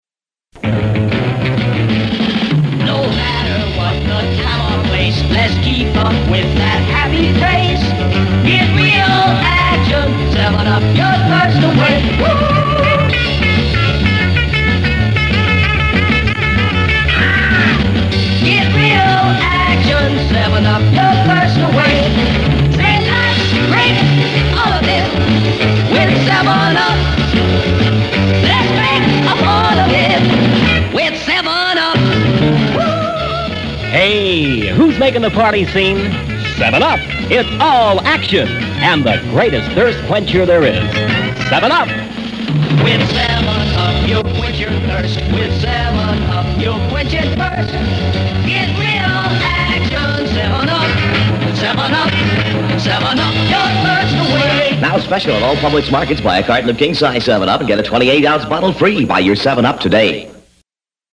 Commercials
Here's a sampling of South Florida 60s era commercials